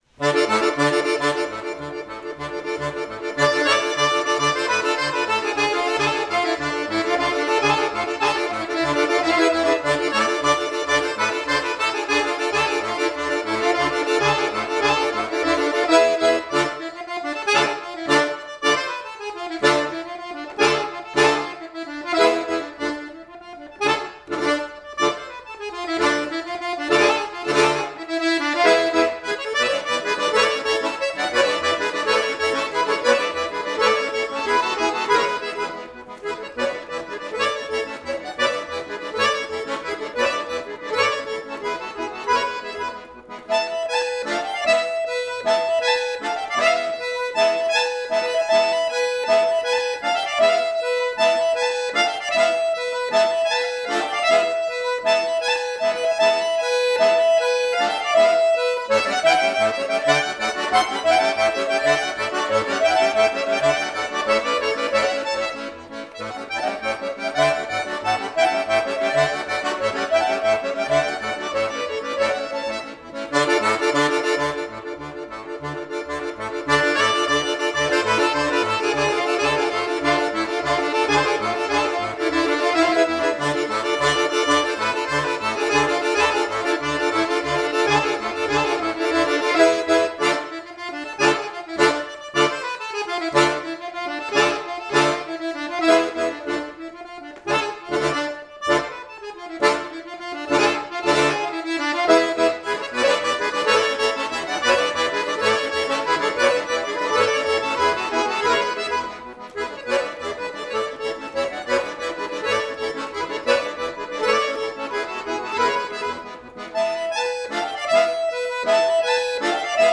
H�r er fyrst og fremst um t�nleikahlj��rit a� r��a. �au eru oft skemmtilegri og meira lifandi en hlj��rit �r hlj��verum �ar sem allt er dau�hreinsa�.
Nota�ir voru tveir Sennheiser ME-64 hlj��nemar. �eir v�su�u hvor a� ��rum og myndu�u um 100� horn. �eir voru um 2 m fr� t�nlistarm�nnunum � u.�.b. 1,6 m h��.